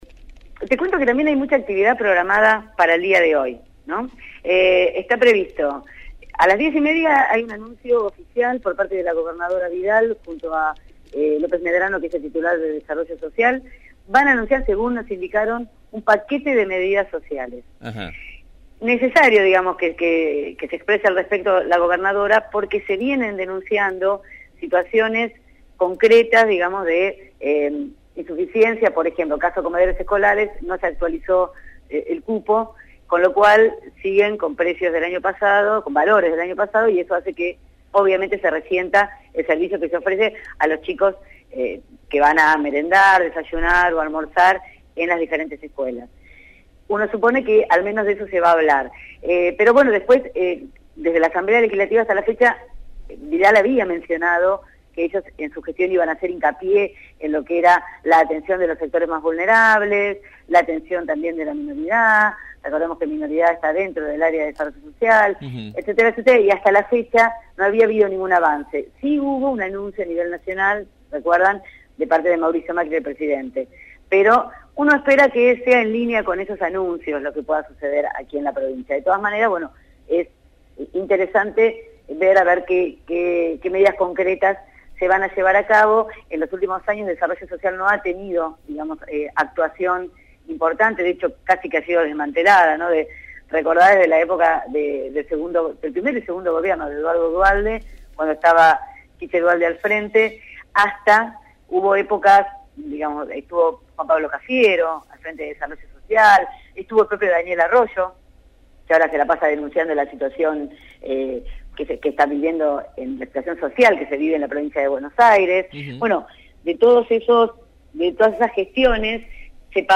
realizó su habitual informe sobre la actualidad política bonaerense. En esta oportunidad se refirió al anuncio de un paquete de medidas sociales por parte del gobierno de María Eugenia Vidal y a la movilización de policías bonaerenses nucleados en SIPOBA (Sindicato de Policías Bonaerenses) a Casa de Gobierno en reclamo de mejoras salariales.